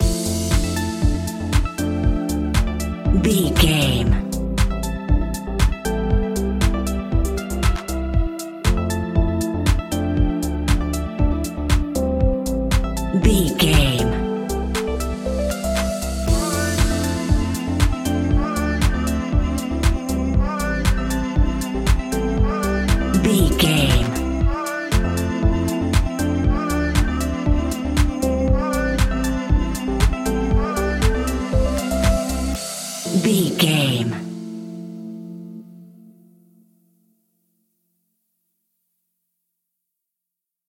Ionian/Major
groovy
uplifting
energetic
bouncy
electric piano
synthesiser
drum machine
vocals
electronic music
synth bass